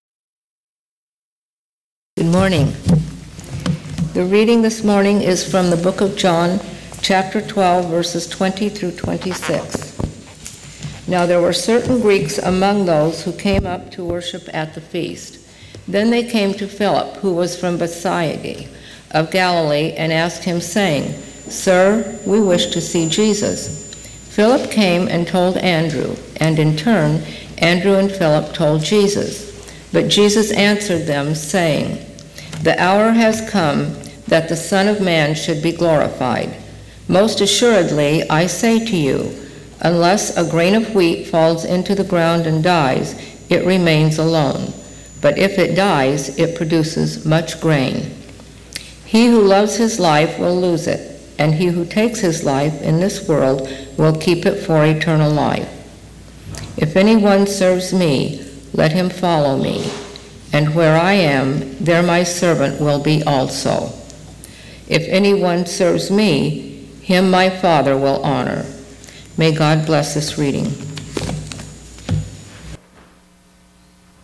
Liturgist
SUNDAY, MARCH 18 FIFTH SUNDAY OF LENT John 12:20-26 “Chain of Command”